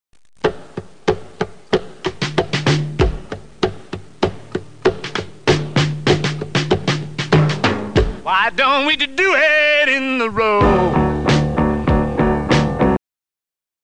The Mono Mix
the beginning is missing the hand clapping.